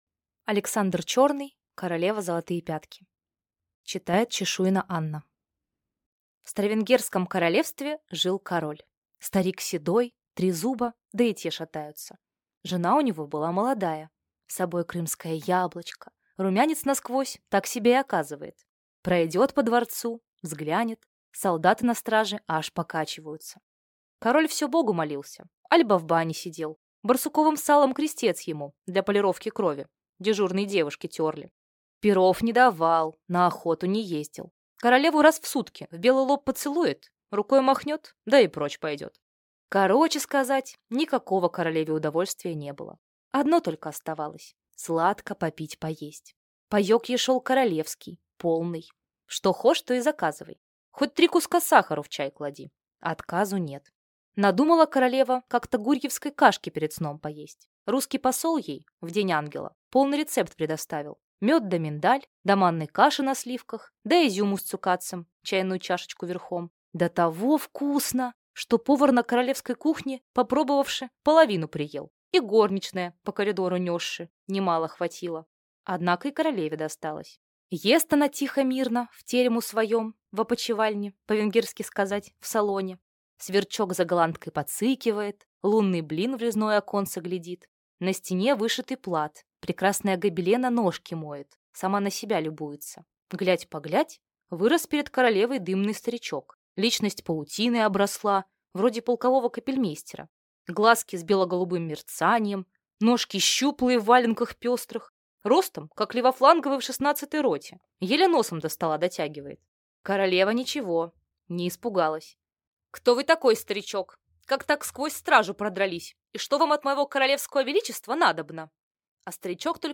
Аудиокнига Королева – золотые пятки | Библиотека аудиокниг